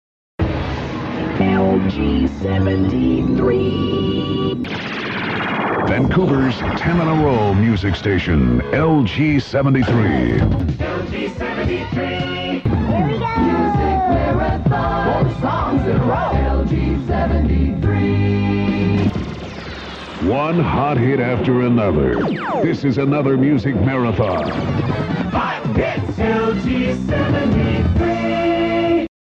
Jingle Montage